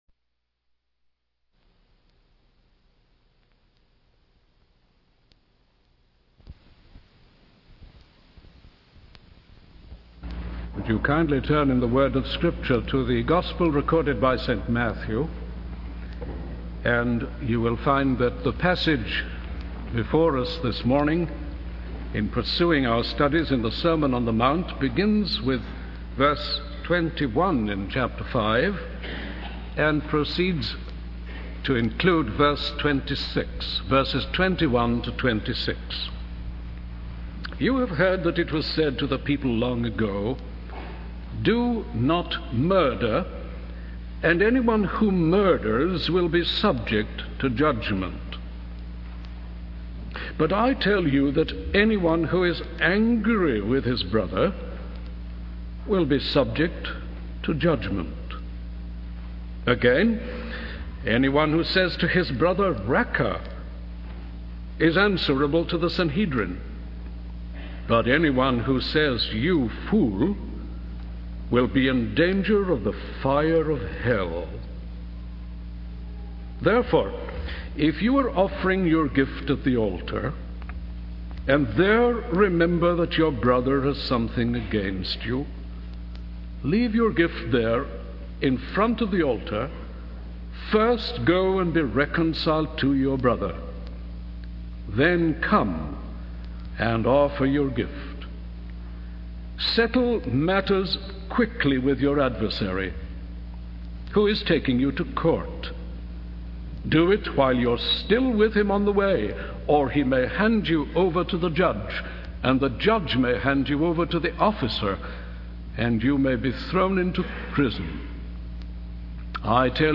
The speaker then moves on to discuss the urgency of settling matters with an adversary before going to court, using the example of unpaid dues. The sermon is titled 'Murder, the Bud and the Bloom,' highlighting the need to address and prevent murder at its earliest stages.